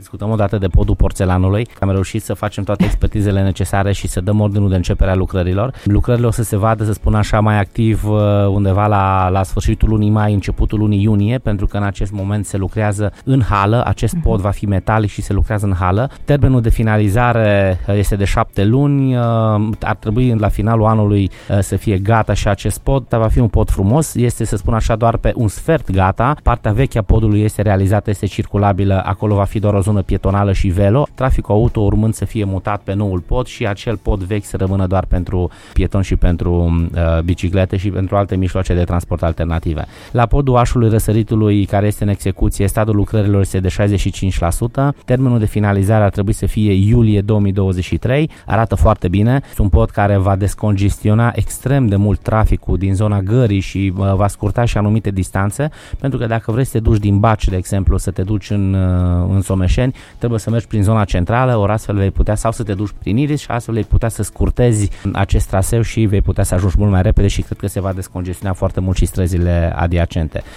Viceprimarul Dan Tarcea ne oferă detalii despre stadiul lucrărilor la aceste poduri.
Viceprimarul Clujului, Dan Tarcea.